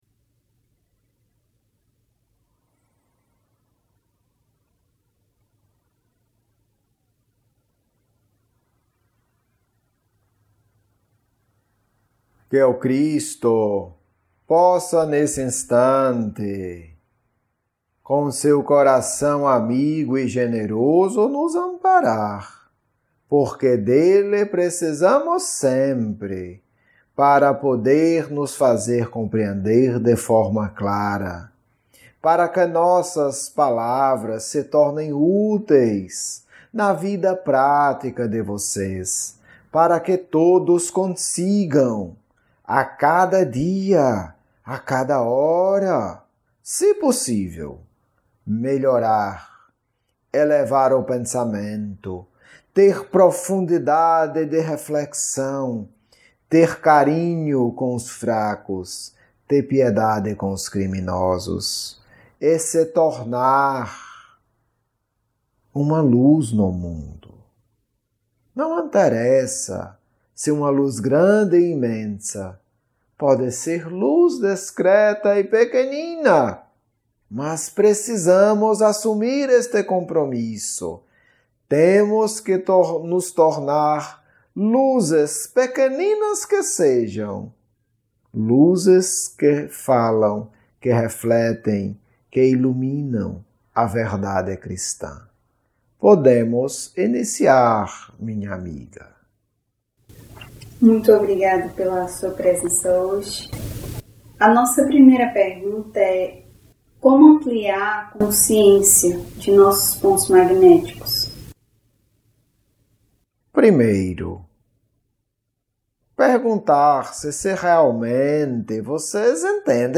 Estudo 2 – Pontos magnéticos centrais e ansiedade 1 - Diálogo mediúnico